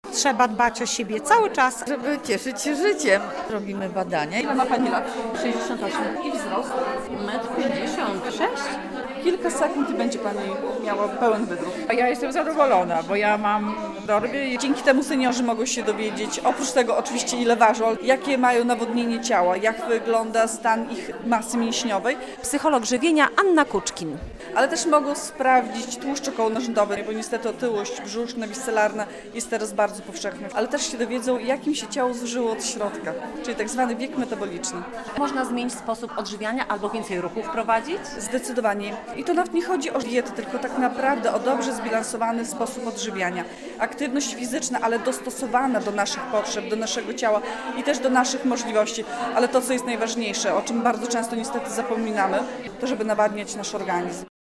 W Klubie Seniora starsze osoby spotkały się z psychologiem żywienia - relacja